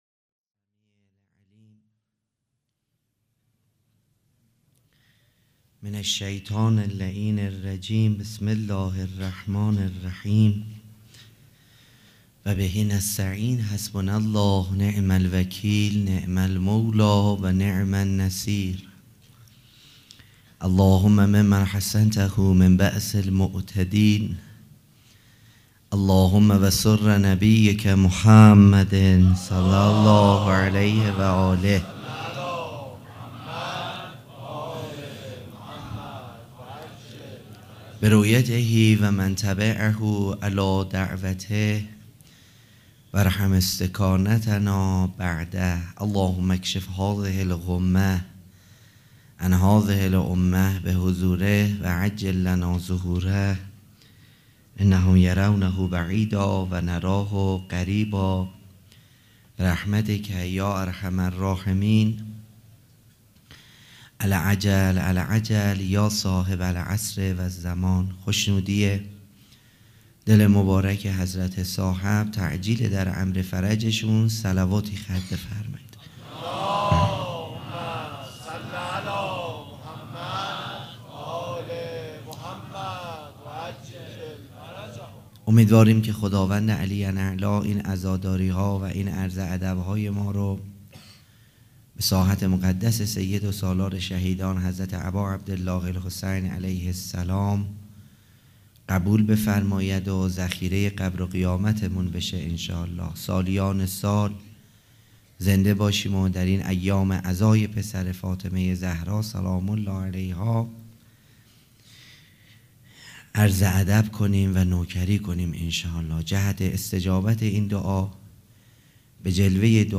سخنرانی
سخنرانی شب چهارم مراسم عزاداری صفر